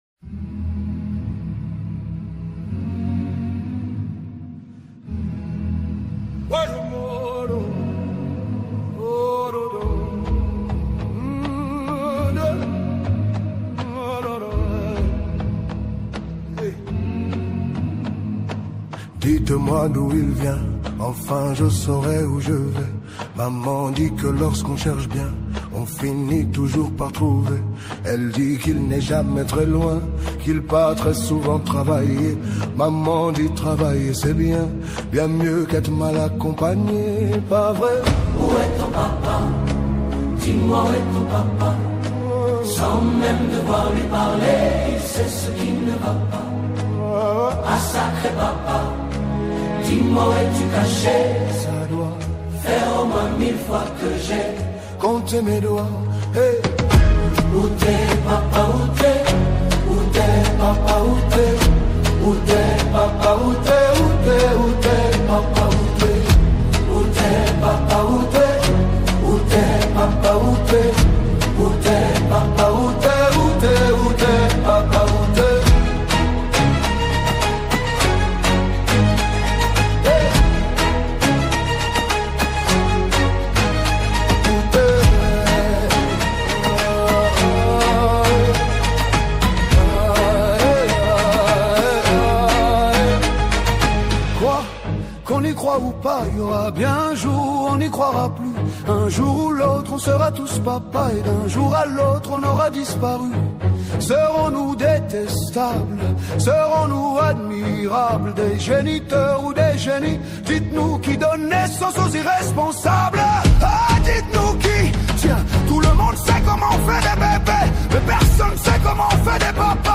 Afro Soul